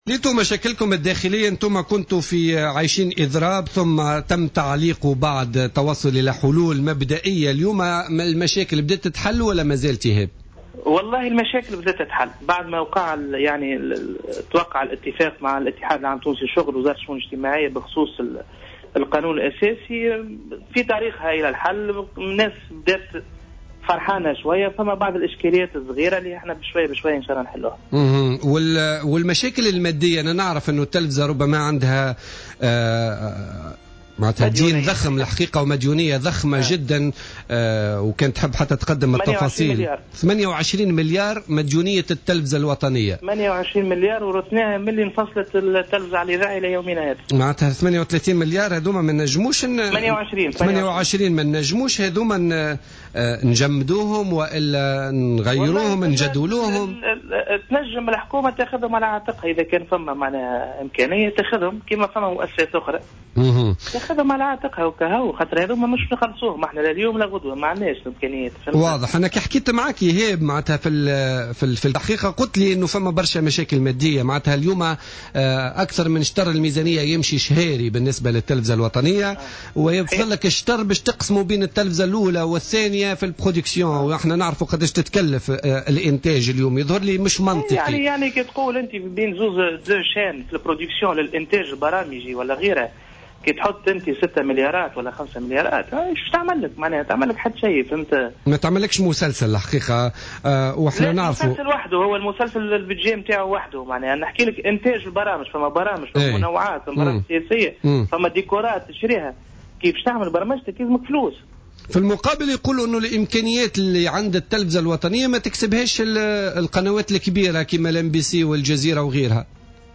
في مداخلة له في برنامج "بوليتيكا"